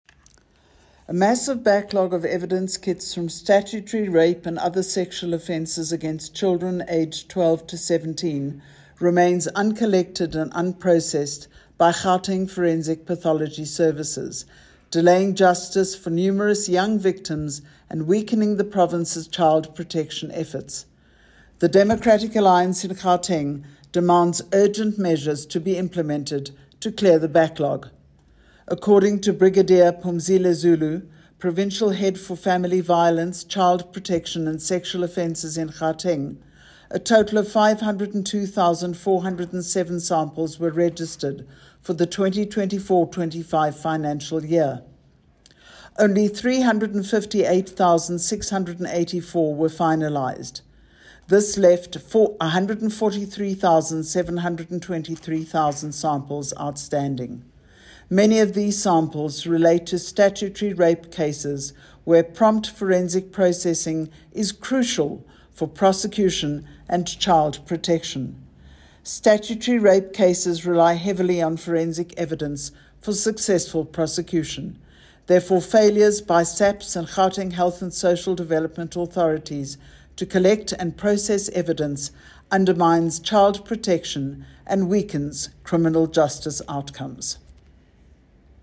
English soundbite by Madeleine Hicklin MPL.